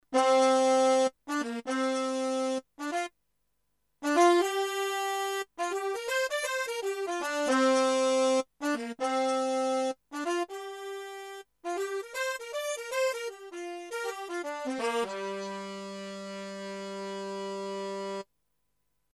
- 1000 HX Horn expander: 20 note polyphonic module packed with 100 presets including Trumpet, Trumpet Mutes, trombones and saxophone.
Synthesis: PCM rompler
demo BRASS 1